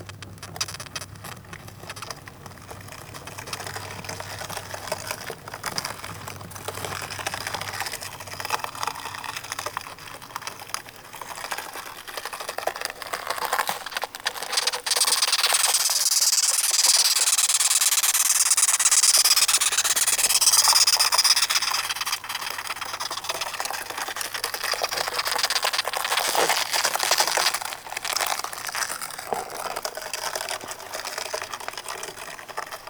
• recording of a velvet red ant - 2.wav
velvet_red_ant_3_3si.wav